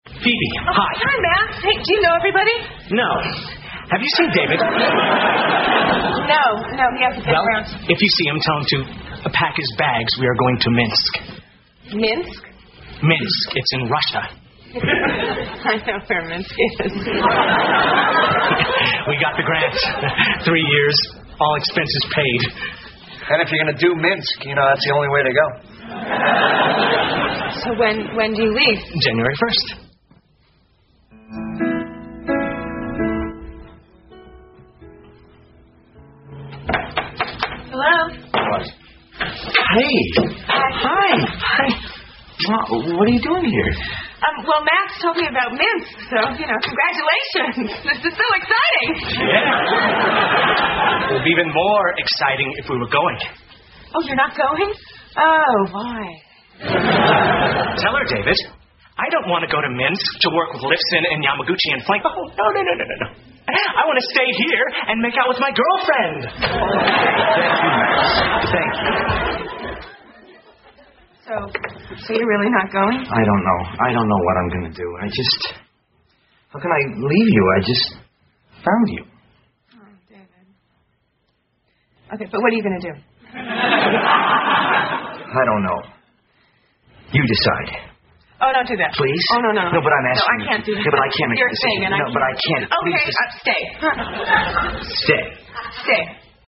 在线英语听力室老友记精校版第1季 第120期:猴子(8)的听力文件下载, 《老友记精校版》是美国乃至全世界最受欢迎的情景喜剧，一共拍摄了10季，以其幽默的对白和与现实生活的贴近吸引了无数的观众，精校版栏目搭配高音质音频与同步双语字幕，是练习提升英语听力水平，积累英语知识的好帮手。